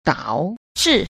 9. 導致 – dǎozhì – đạo trí (dẫn đến, xảy đến)
dao_zhi.mp3